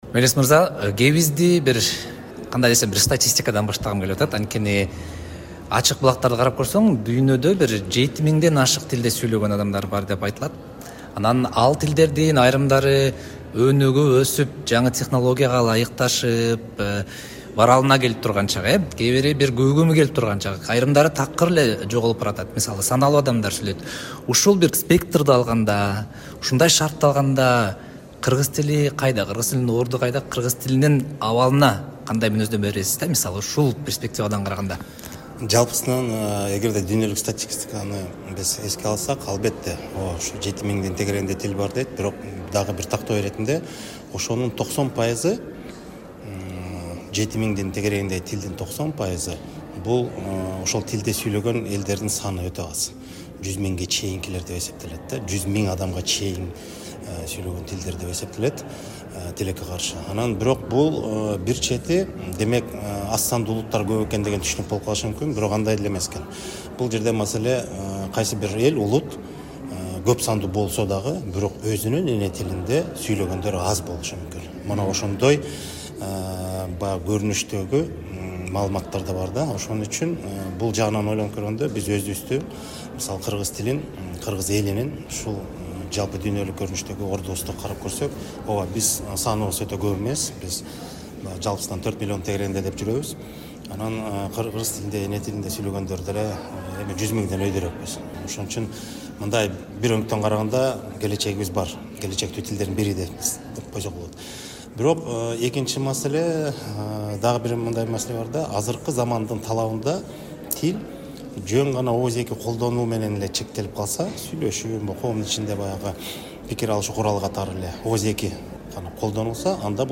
Аны утурлай кыргыз тилинин абалы, аны өнүктүрүүгө тоскоол болгон жагдайлар тууралуу президентке караштуу Мамлекеттик тил жана тил саясаты боюнча улуттук комиссиясынын төрагасы Мелис Мураталиев “Азаттыкка” ат жалында маек курду.